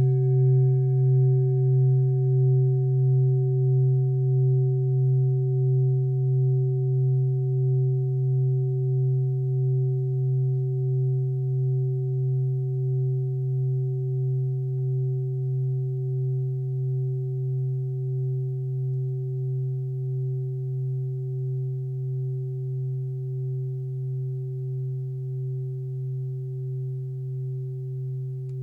Klangschale TIBET Nr.38
Klangschale-Gewicht: 1350g
Klangschale-Durchmesser: 21,0cm
Sie ist neu und ist gezielt nach altem 7-Metalle-Rezept in Handarbeit gezogen und gehämmert worden..
(Ermittelt mit dem Filzklöppel oder Gummikernschlegel)
Diese Frequenz kann bei 160Hz hörbar gemacht werden; das ist in unserer Tonleiter nahe beim "E".
klangschale-tibet-38.wav